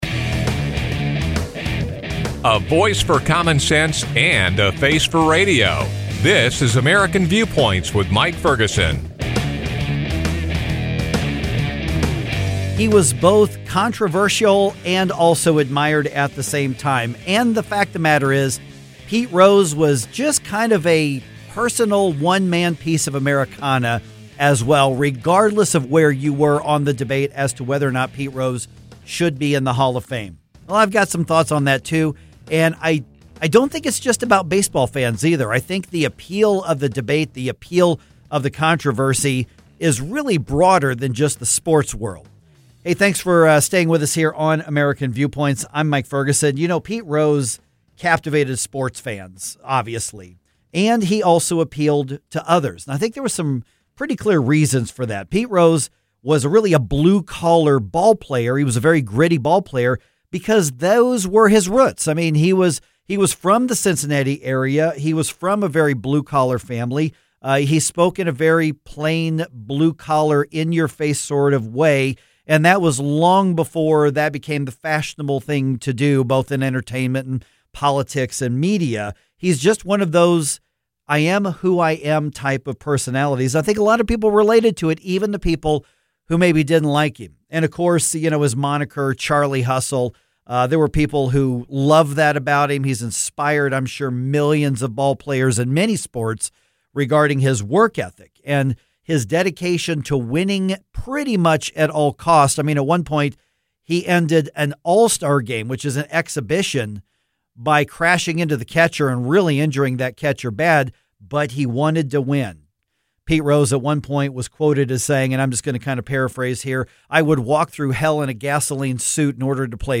New research indicates that the pressure of being connected online and on text could come at a cost to our mental and physical health. In this discussion